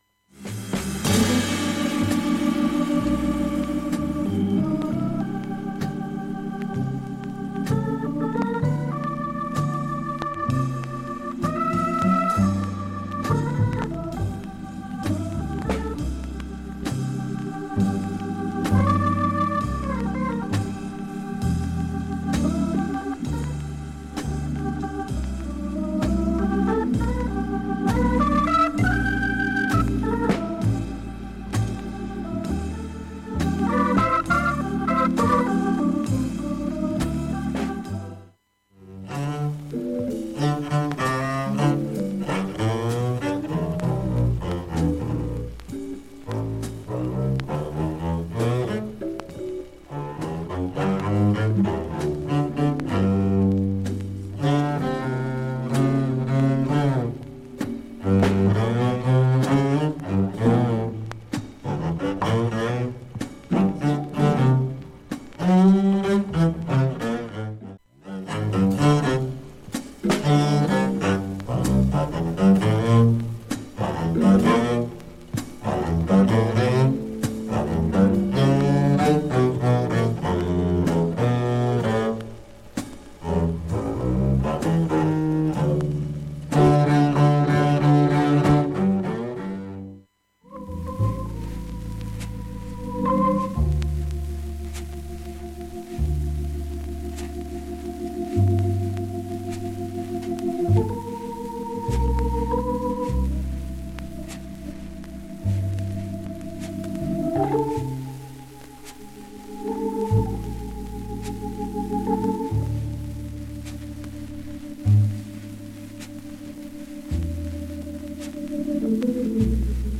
よくあるサーフェス音が出ますが、
この盤の素材にしてはましで普通な感じです。